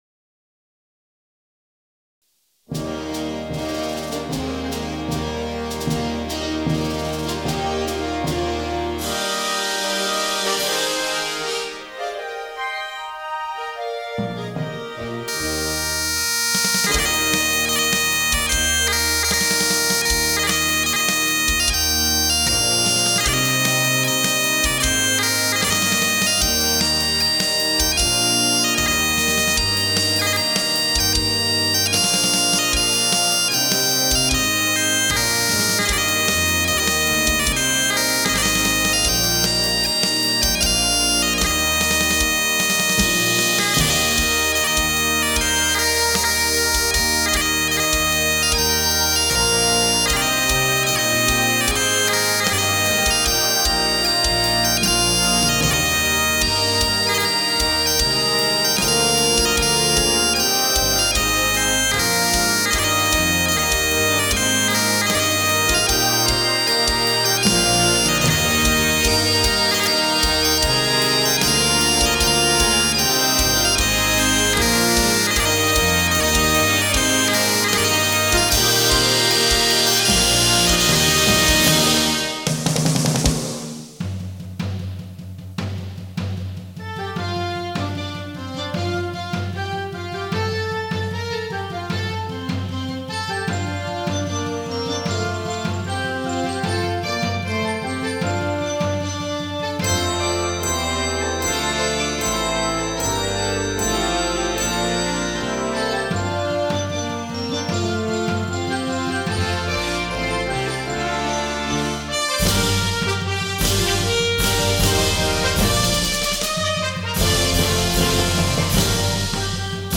Timpani
Bodhran
Glockenspiel